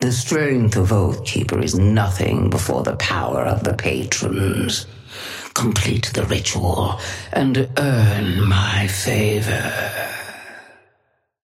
Sapphire Flame voice line - The strength of Oathkeeper is nothing before the power of the patrons.
Patron_female_ally_ghost_start_03.mp3